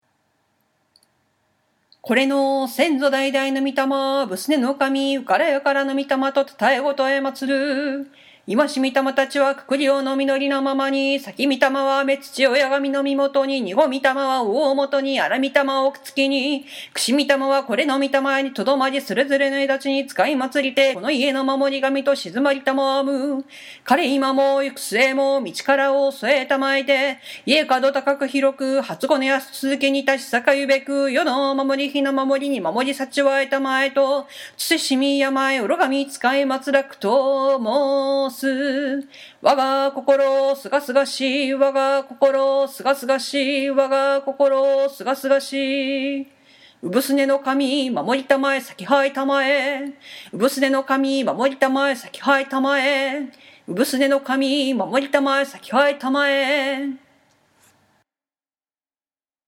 ご先祖様にお礼を伝える祝詞で